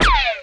impact_projectile_003.wav